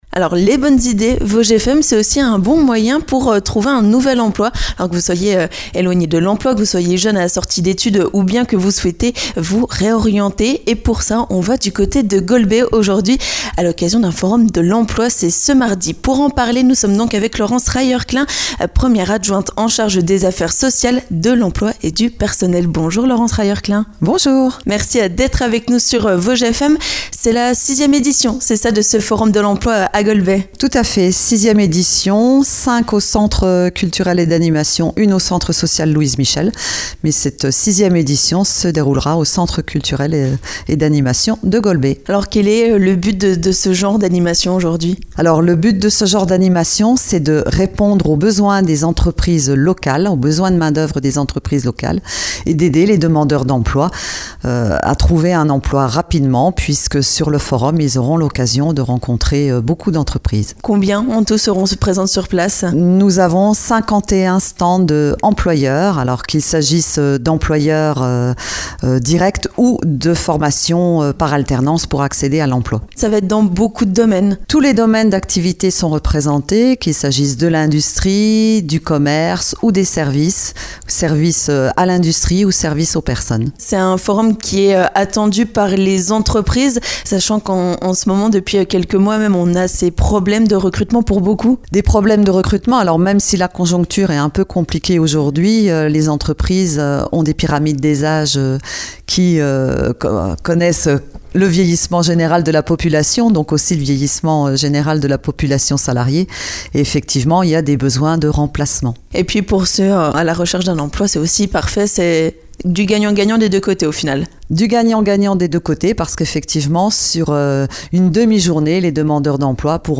Nous en parlons avec la première adjointe à la municipalité de Golbey, Laurence Rayeur-Klein.